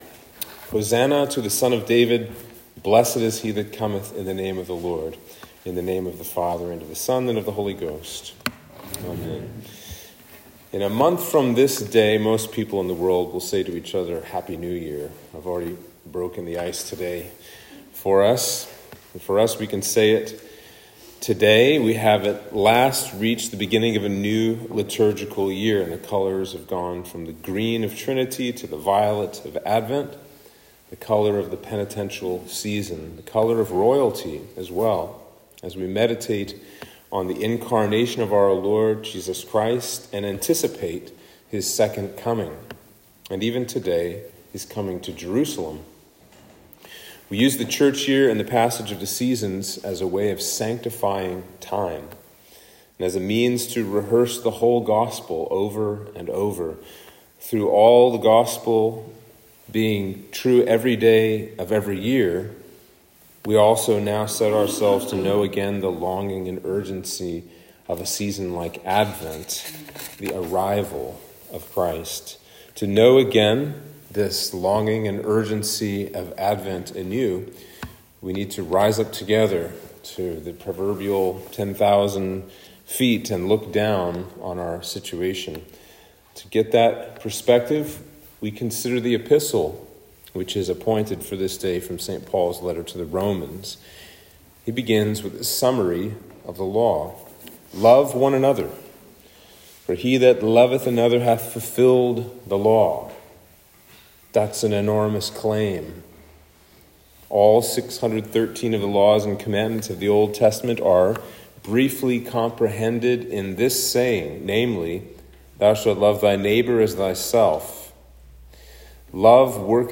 Sermon for Advent 1